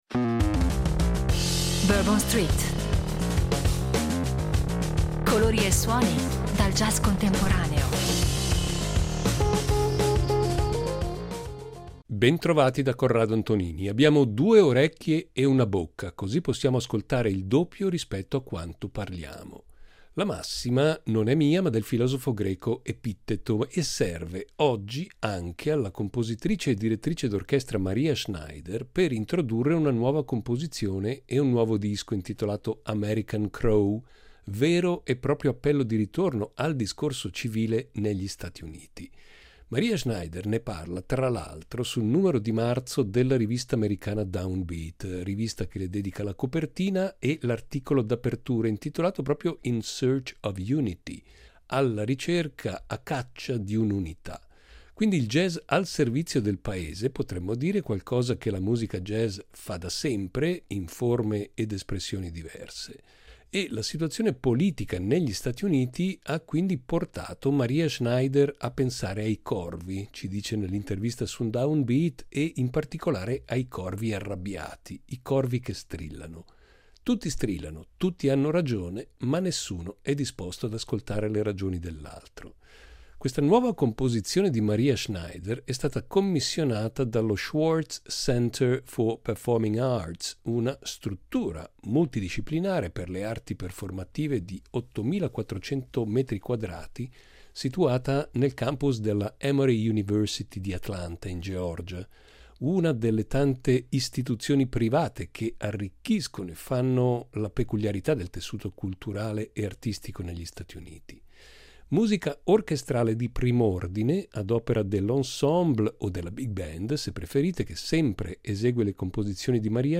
Musica orchestrale di prim’ordine ad opera di una delle più originali compositrici jazz di oggi. La necessità dell’ascolto sarà il tema portante di questa puntata di “ Bourbon Street ”.
Niente prove, nessuna composizione, nessuna aspettativa, solo il piacere di suonare assieme nel momento.